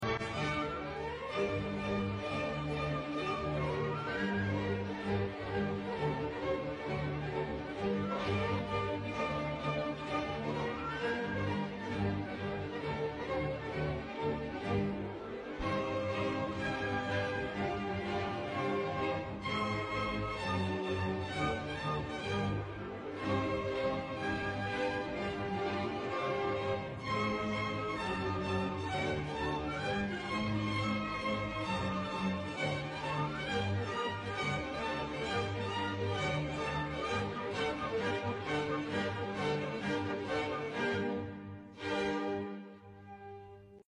a movement of breathtaking power and relentless energy